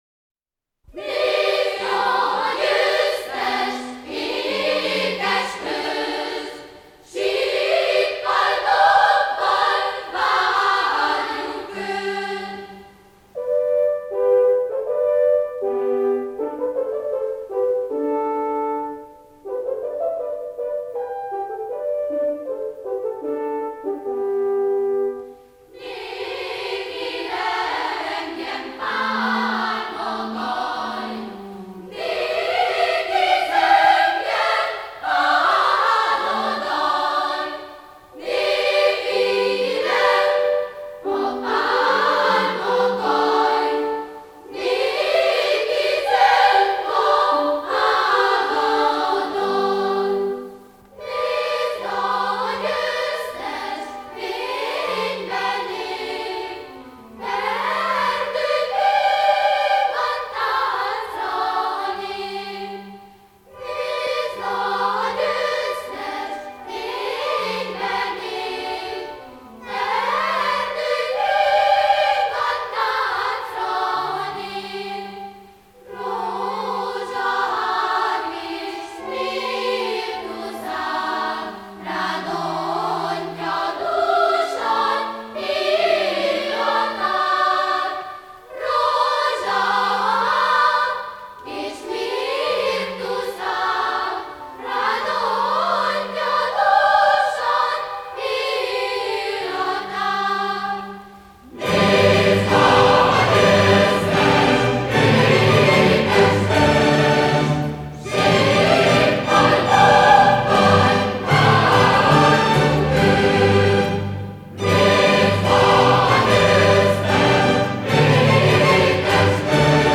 judas-maccabaeus-gyozelmi-korus.mp3